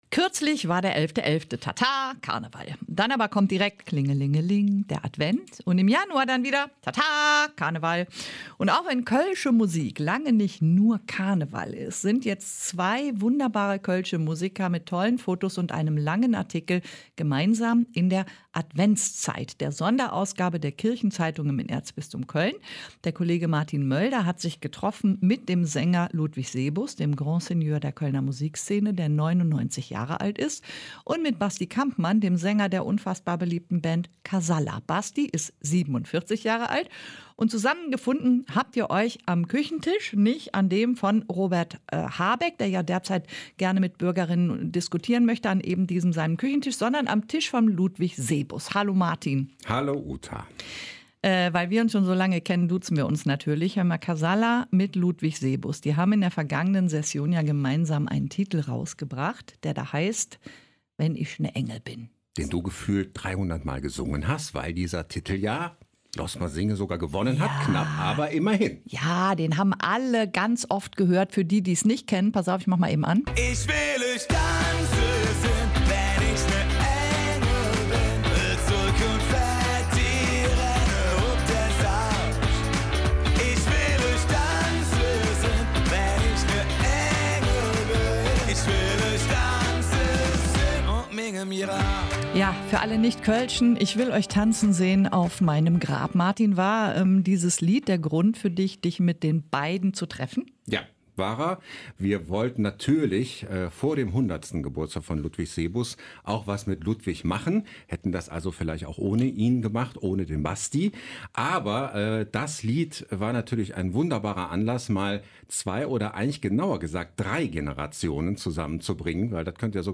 Ein Interview
(Journalist)